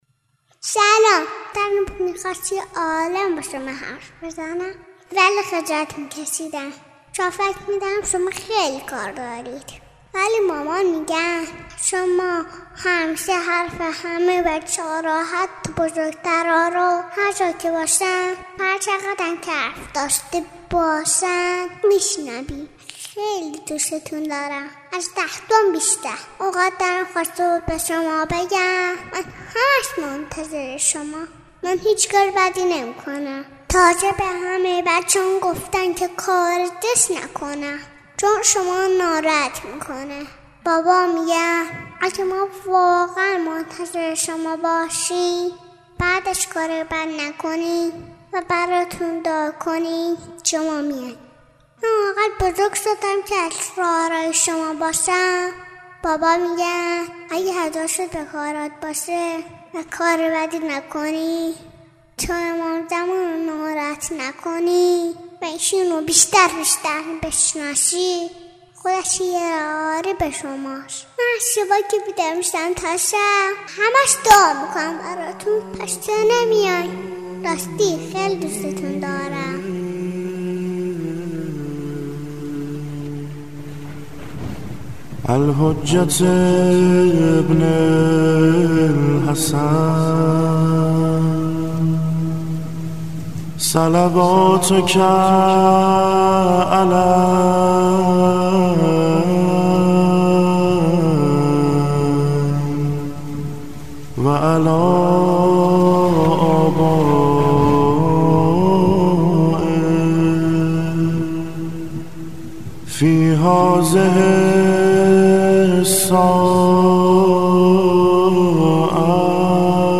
مناجات کودکانه باامام زمان ارواحنافداه.mp3